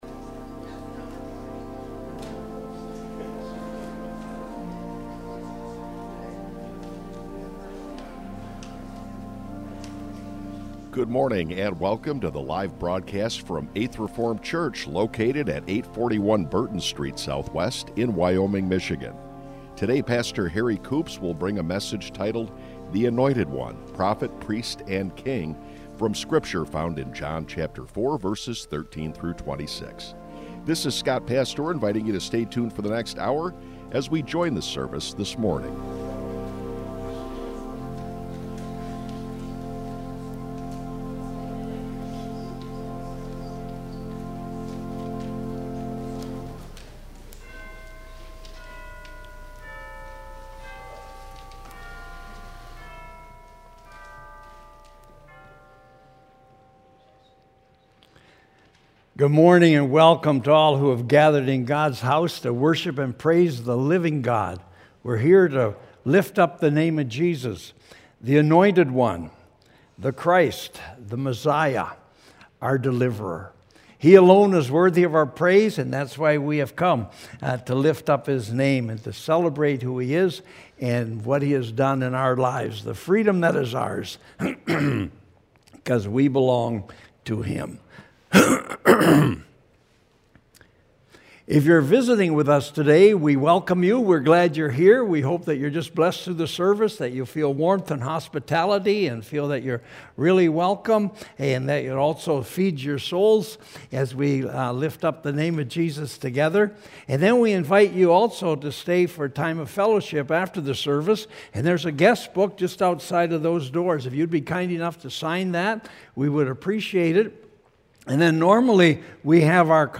Worship Services | Eighth Reformed Church
Current Sermon